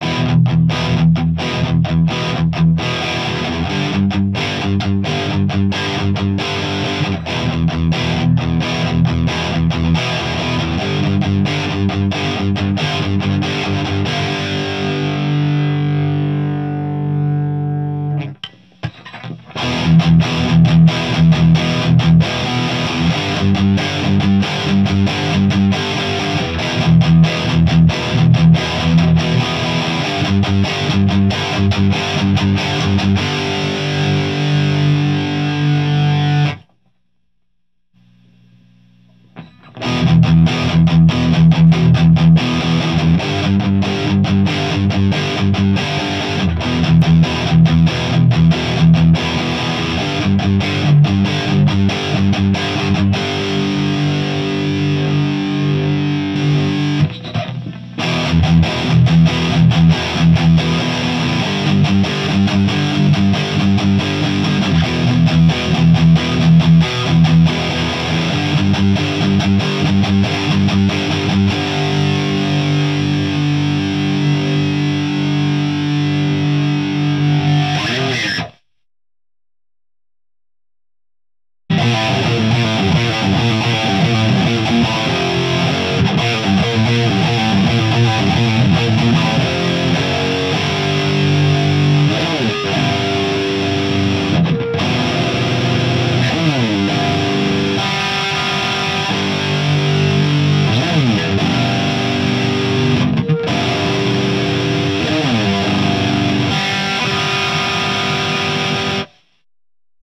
PA2は10dB程度の設定です。
今回は、ゲイン5　トーンシフトオン
MTRはMRS-8を使いました。マイクはSM57 PG57
ULTRA GAIN
LEED2+PA2の順番です。